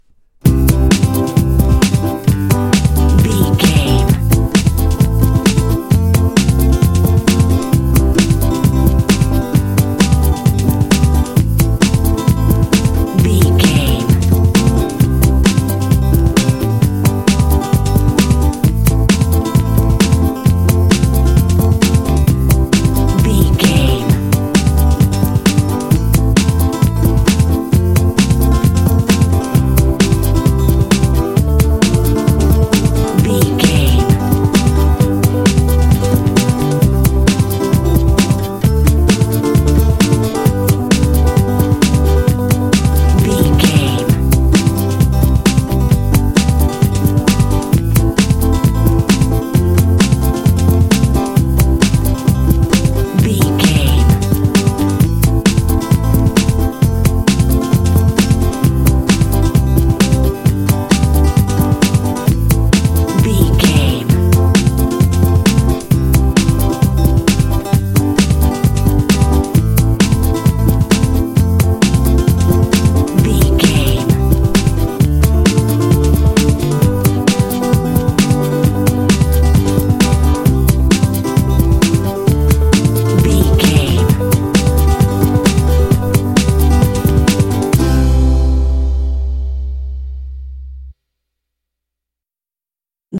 Ionian/Major
happy
fun
drums
banjo
bass guitar
playful